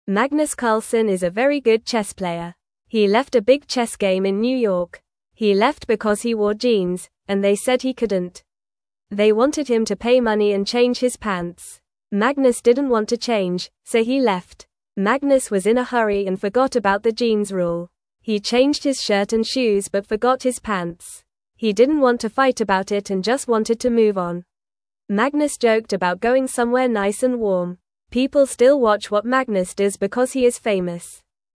Fast
English-Newsroom-Beginner-FAST-Reading-Magnus-Carlsen-Leaves-Chess-Game-Over-Jeans-Rule.mp3